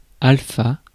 Ääntäminen
Synonyymit mâle alpha hélion particule alpha particule α α surperformance Ääntäminen Tuntematon aksentti: IPA: /al.fa/ Haettu sana löytyi näillä lähdekielillä: ranska Käännös Substantiivit 1. алфа {f} Suku: m .